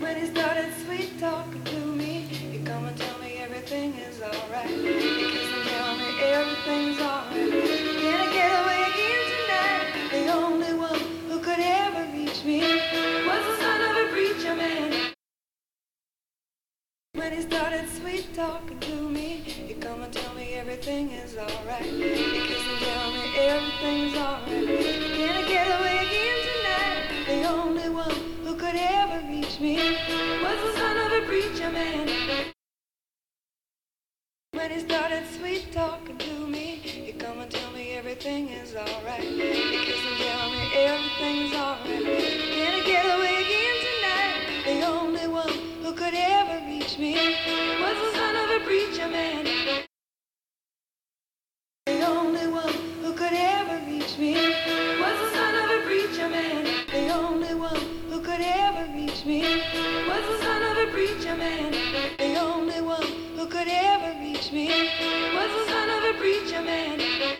För att verifiera att Rödemikrofonen fungerar som den ska gjorde jag motsvarande inspelning på en H4n.
Intern mic i 7D - Intern mic i Zoom H4n - Röde SVM på H4n - Tre korta utsnitt från föregående.
Det är sällan någon klagar på bruset i Zoom H4n, ändå blir brusnivån lägre med kamerans inbyggda mikrofon, så i det avseendet är den inbyggda mycket bra.
Att bruset från H4n är ganska hörbart beror på den höga inspelningsnivån (90/100) samt att det går åt ytterligare 15 dB i efterbehandlingen för att bara motsvara kamerans ljudnivå.